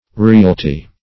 Realty - definition of Realty - synonyms, pronunciation, spelling from Free Dictionary